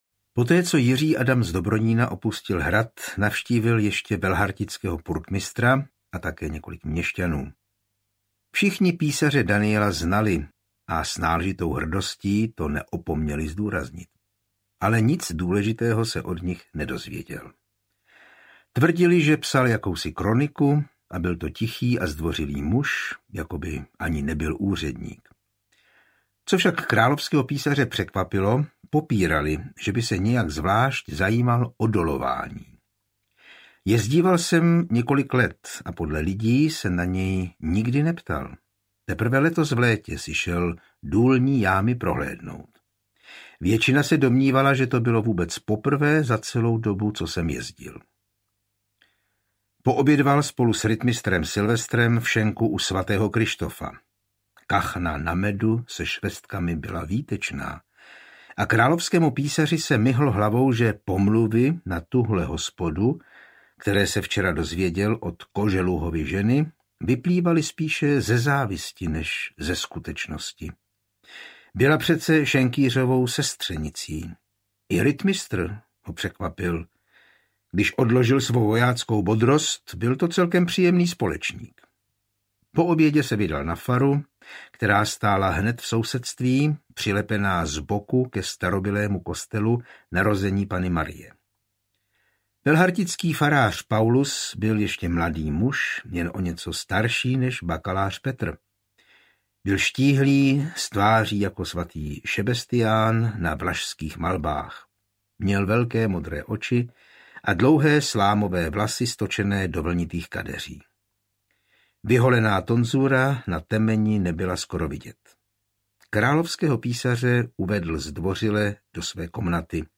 Ukázka z knihy
Audiokniha je bez hudebních předělů a podkresů.